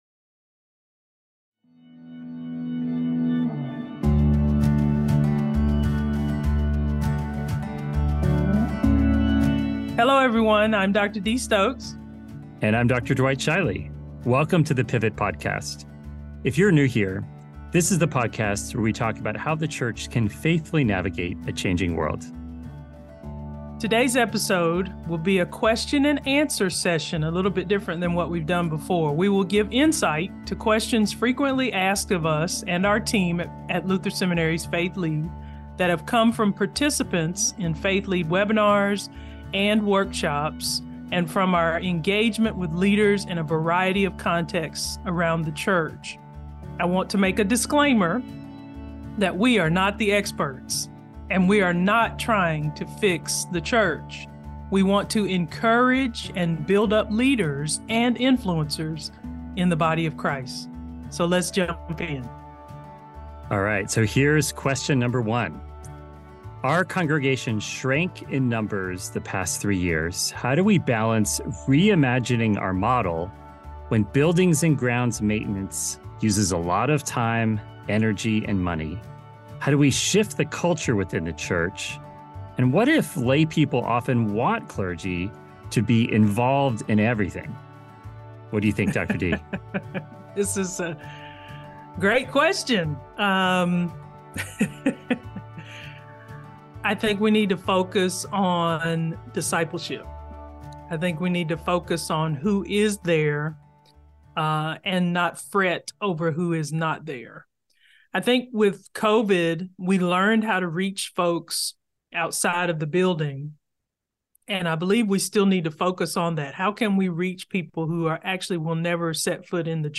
Questions that listeners asked include: